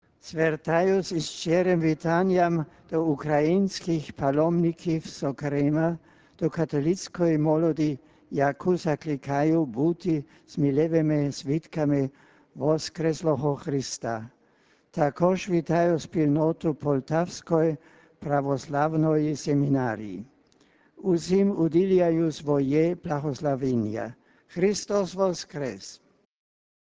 Також Папа вітаючи паломників з різних країн, окремо привітав українською мовою паломників з України: